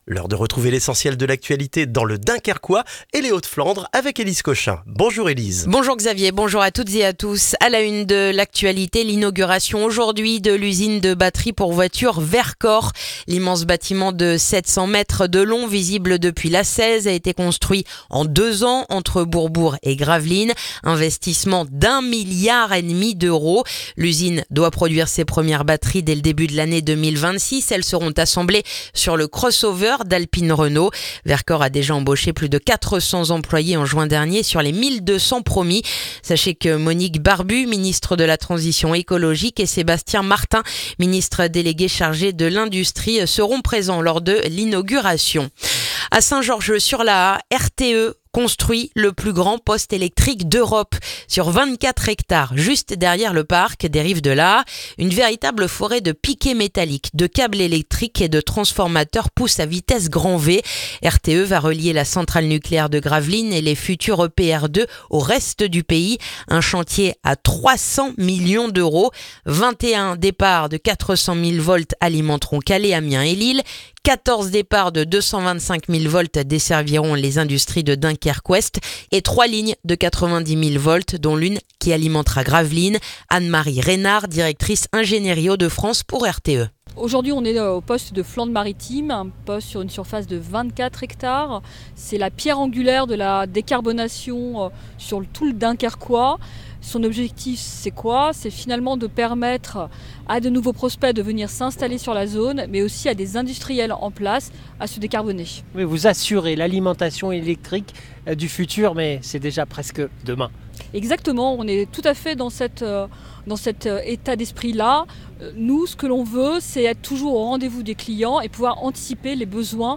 Le journal du jeudi 11 décembre dans le dunkerquois